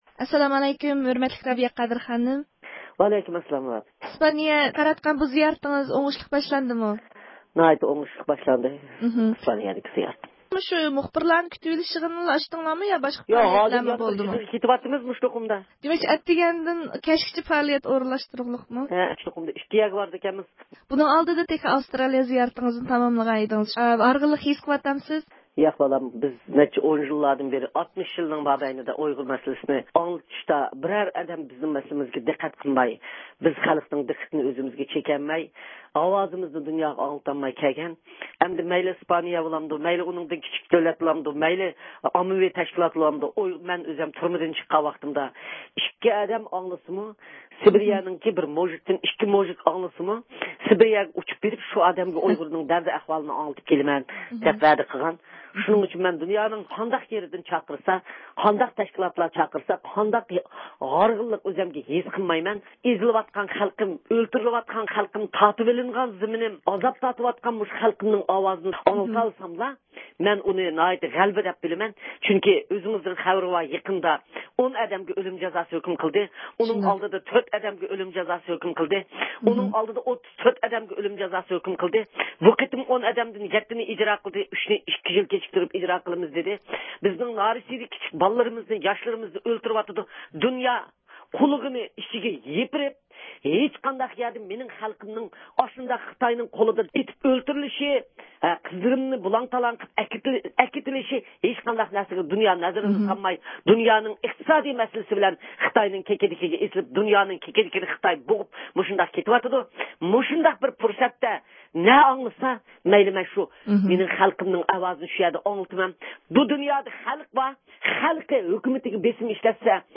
گەرچە رابىيە قادىر خانىمنىڭ بۈگۈنكى پائالىيىتى ناھايىتى زىچ ئورۇنلاشتۇرۇلغان بولسىمۇ ئىسپانىيە ۋە خەلقئارالىق ئاخبارات ئورگانلىرىنىڭ زىيارەتلىرىدىن كېيىن، ئىستانسىمىز مۇخبىرىنىڭ تېلېفون زىيارىتىگە ۋاقىت ئاجرىتىپ، ئۆزىنىڭ ئىسپانىيىگە قاراتقان بۇ قېتىملىق زىيارەت تەسىراتلىرىنى بايان قىلدى.